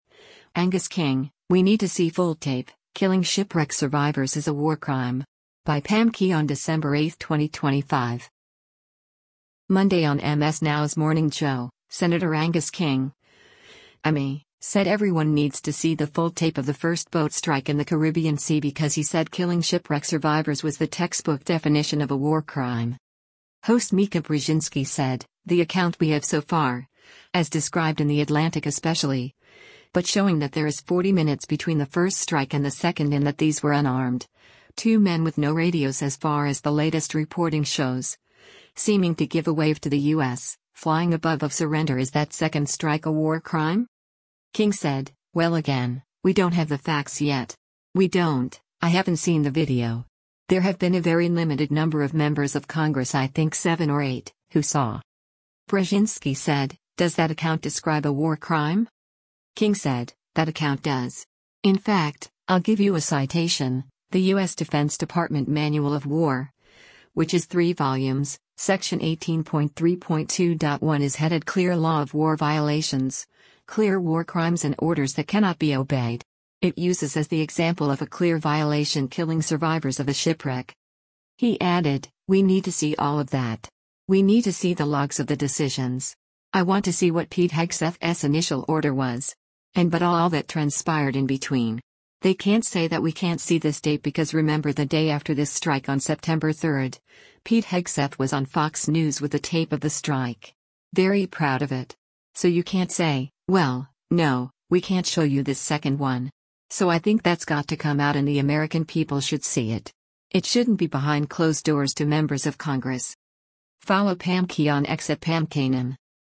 Monday on MS NOW’s “Morning Joe,” Sen. Angus King (I-ME) said everyone needs to see the full tape of the first boat strike in the Caribbean Sea because he said killing shipwreck survivors was the textbook definition of a war crime.